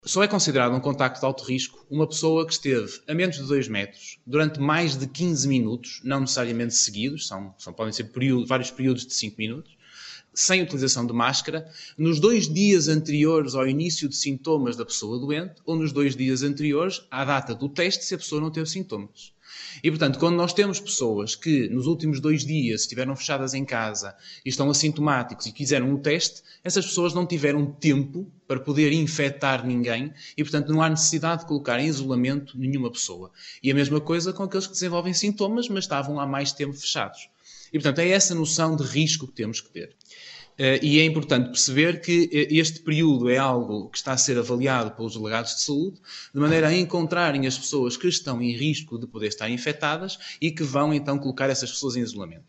Nos Açores, o número de casos positivos quadruplicou entre 25 de outubro e 25 de novembro. A informação foi avançada pelo coordenador regional de Saúde Pública dos Açores, Gustavo Tato Borges, esta quarta-feira, em conferência de imprensa, sobre o ponto de situação da luta contra a pandemia de Covid-19 na Região.